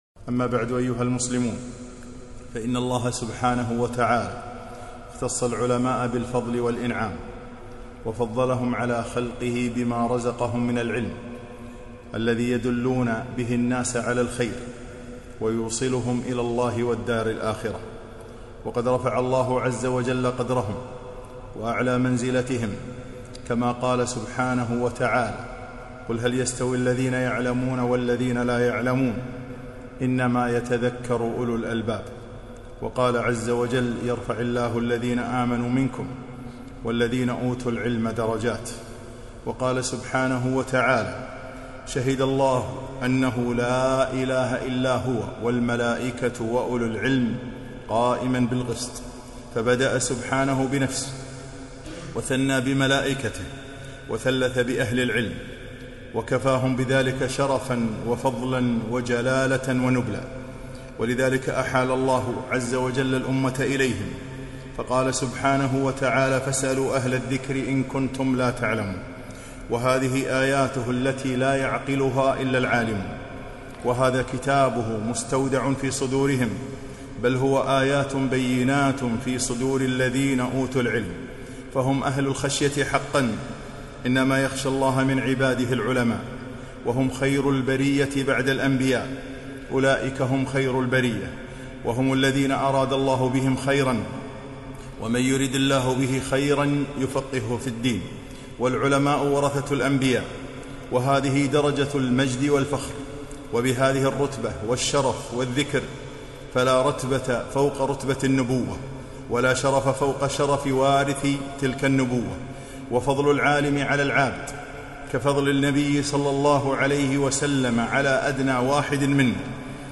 خطبة - ذهاب العلماء